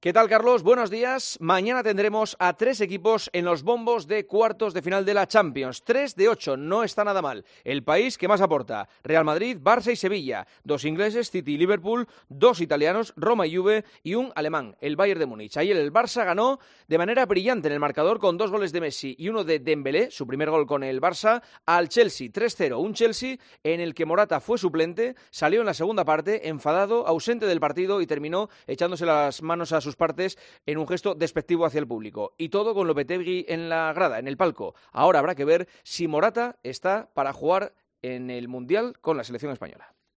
Escucha el comentario del director de 'El Partidazo' de COPE en 'Herrera en COPE'